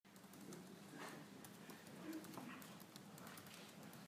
图书馆
描述：图书馆的氛围。
标签： 背景声 沉默 ATMO 音景 大气 环境 环境 背景 西班牙 ATMOS 白噪声 图书馆 氛围 人群中 现场记录 一般的噪声 大气
声道立体声